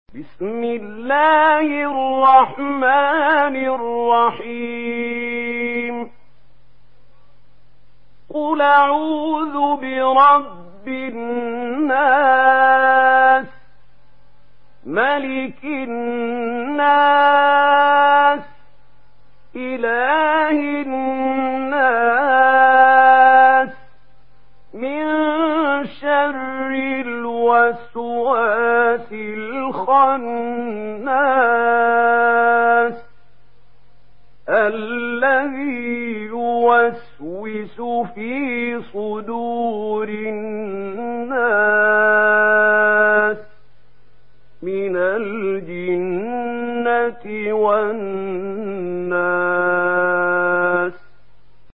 سورة الناس MP3 بصوت محمود خليل الحصري برواية ورش
مرتل